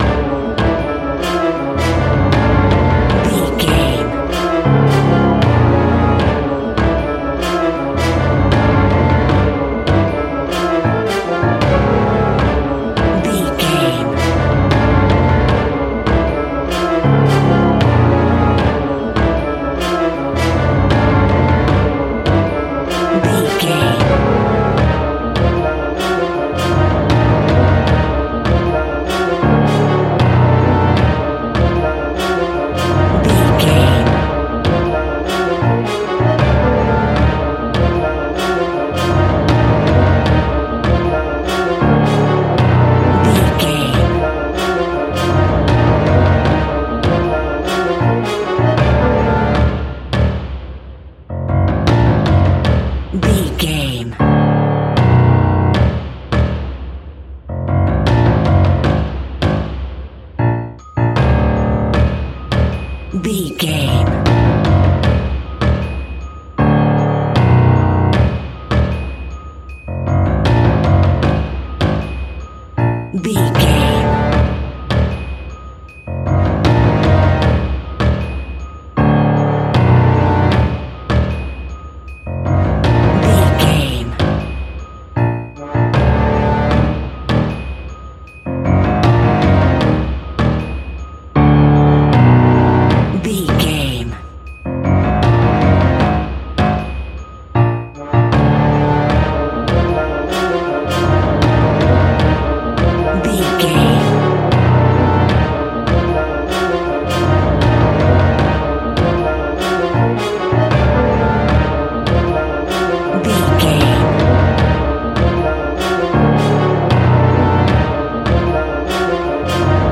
In-crescendo
Thriller
Aeolian/Minor
tension
ominous
haunting
eerie
horror
synthesizers
Synth Pads
atmospheres